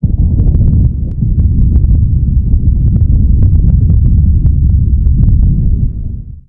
thunder2b.wav